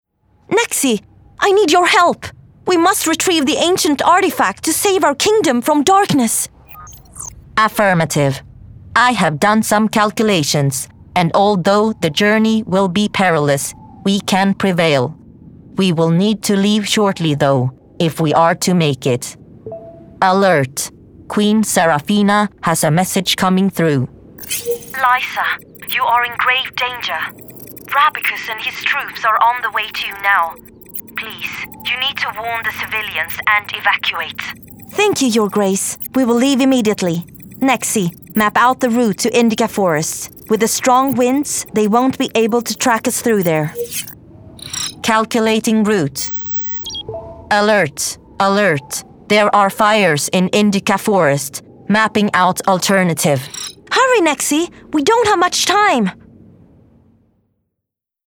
Voice Reel
Video Game